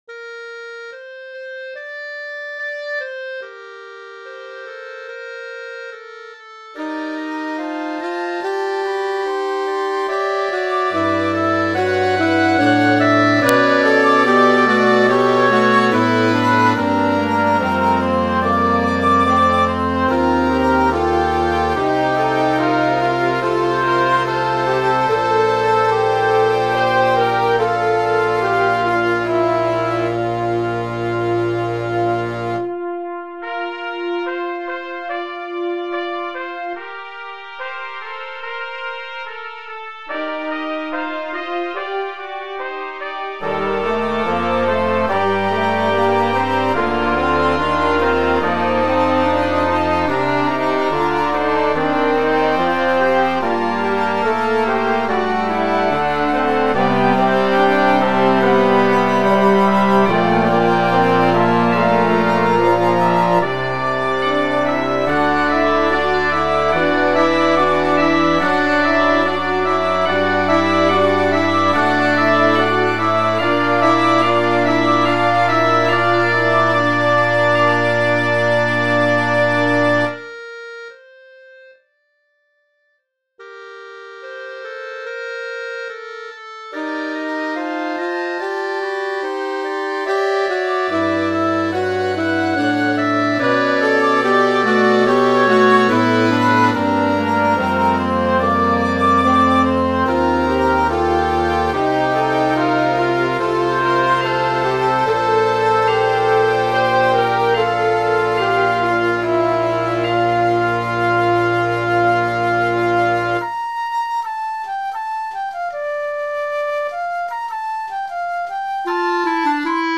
Concert Band - Exhibition Series